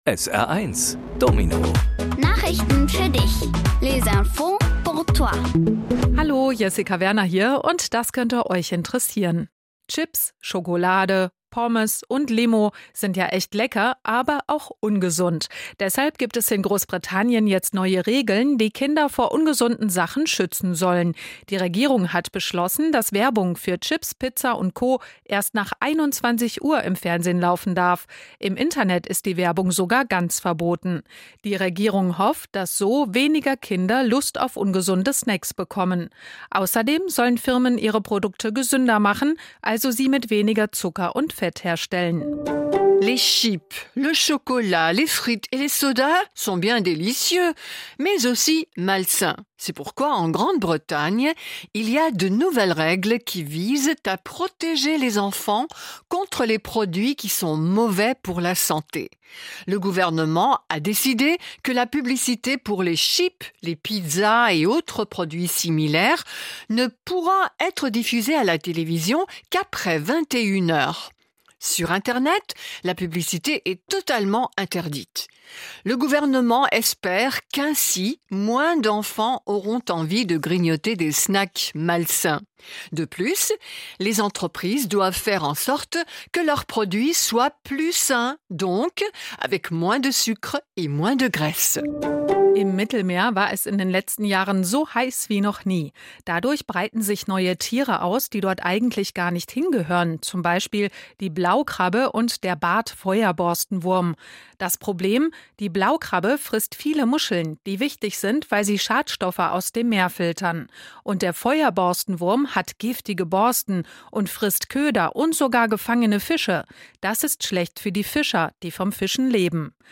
Die wichtigsten Nachrichten der Woche kindgerecht aufbereitet auf Deutsch und Französisch